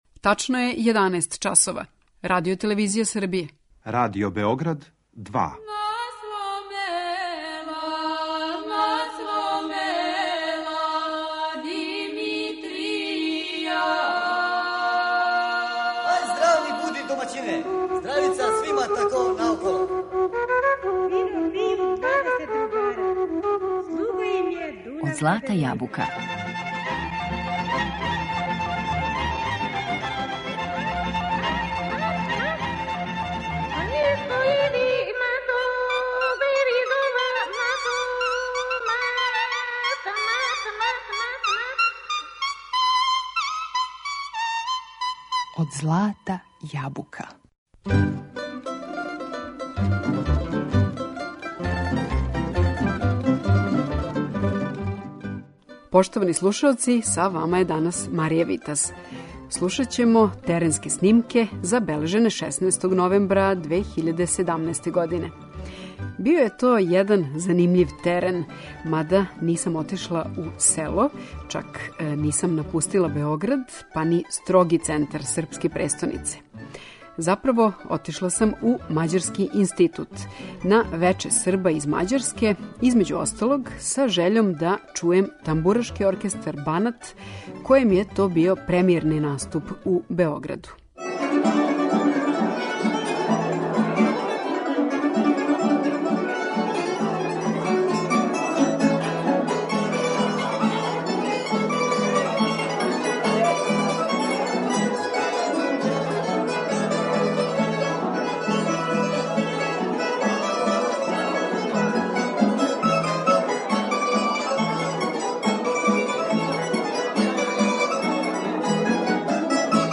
Тамбурашки оркестар 'Банат'
Ансамбл је основан у селу Деска у Мађарској 2005. године при истоименом КУД-у, који ове године обележава седам деценија постојања и неговања пре свега српске културе, музике, обичаја и језика. Тамбурашки оркестар "Банат" је први наступ у Београду имао 16. новембра 2017. у Мађарском институту, у оквиру догађаја "Вече Срба из Мађарске".